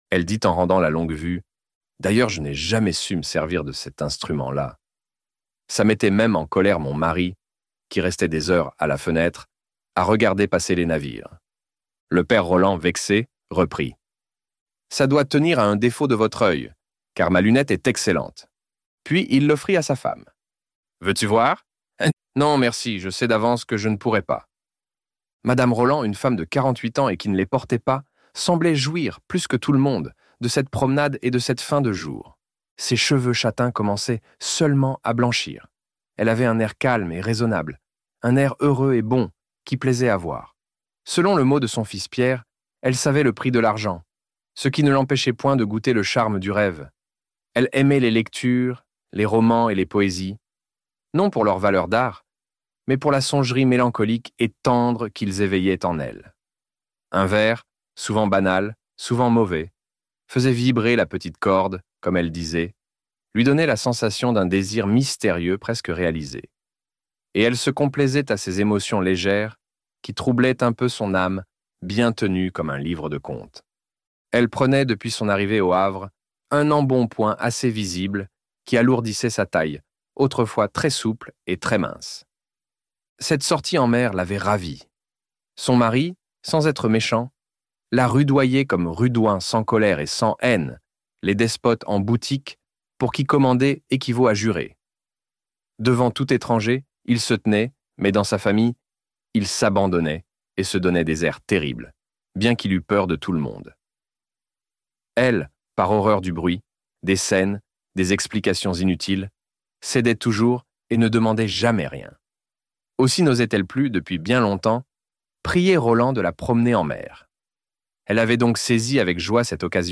Pierre et Jean - Livre Audio